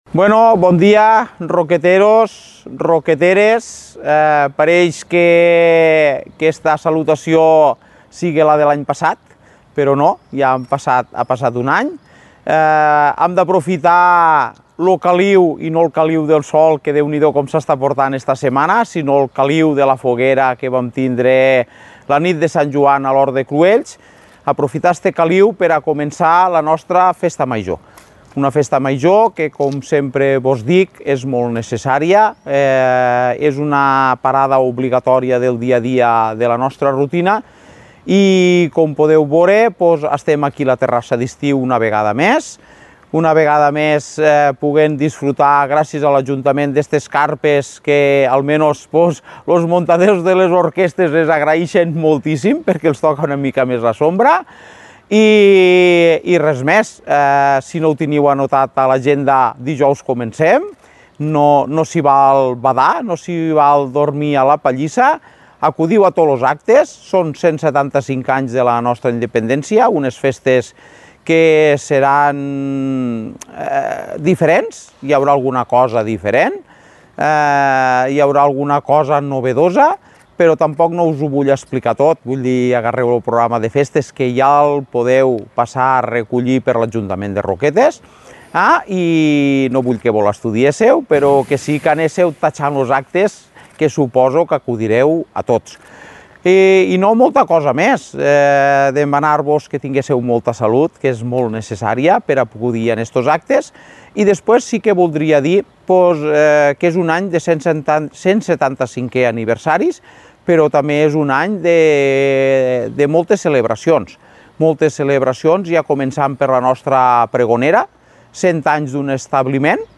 Salutació de Festes Majors de Roquetes 2025